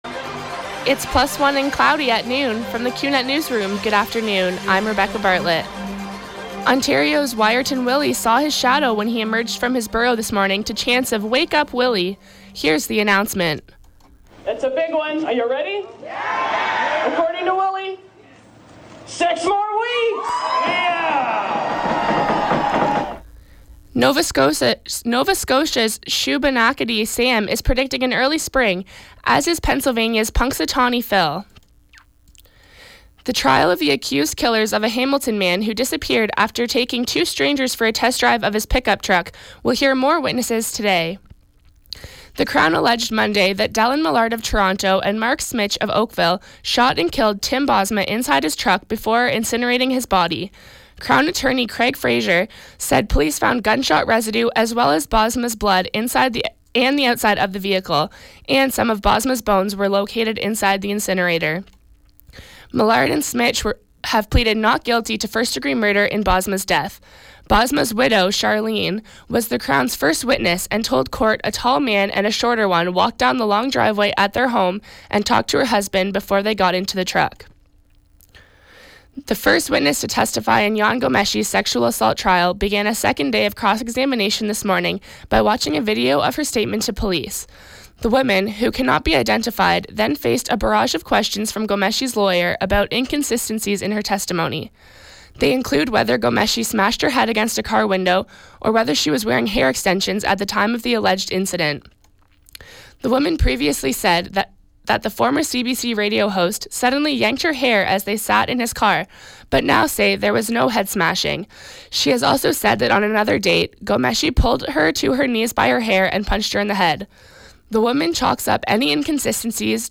91X newscast – Tuesday, Feb. 2, 2016 – 12 noon